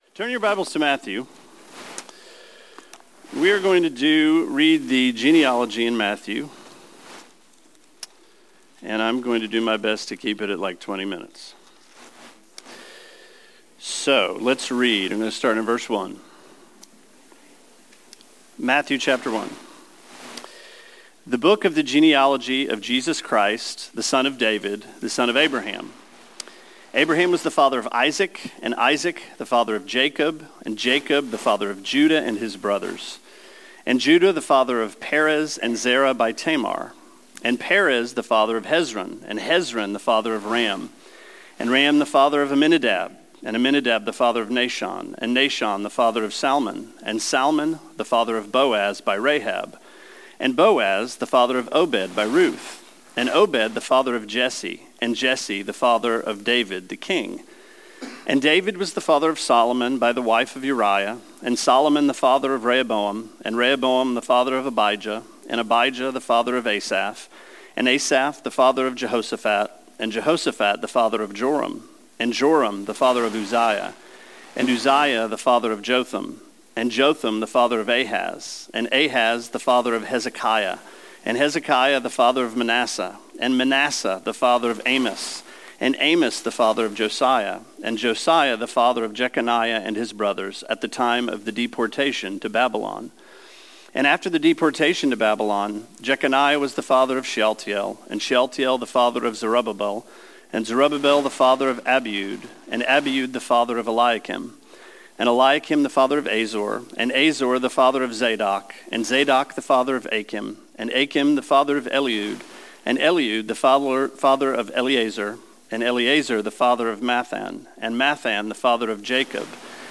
Sermon 11/29: Introduction to Matthew – Genealogy